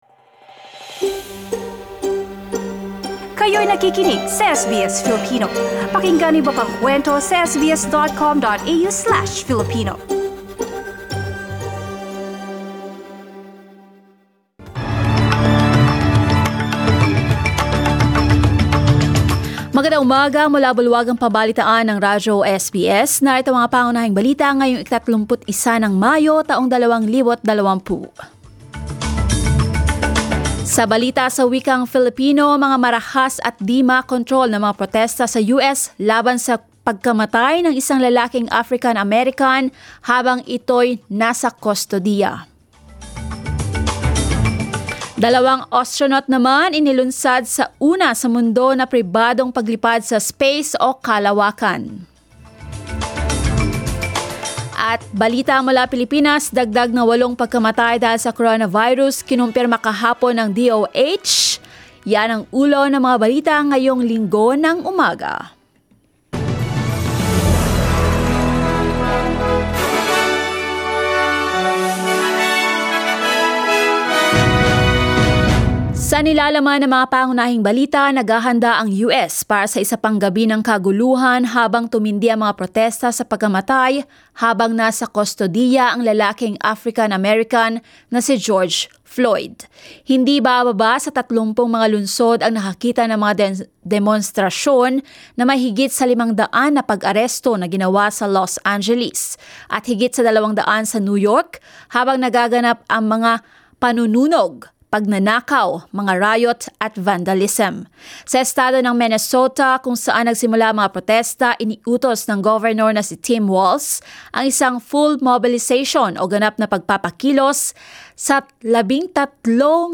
SBS News in Filipino, Sunday 31 May